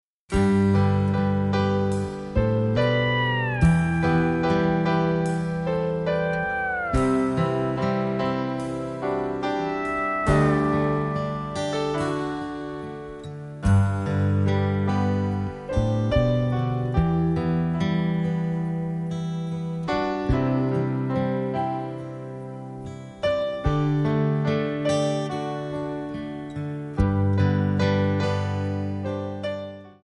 Backing track Karaokes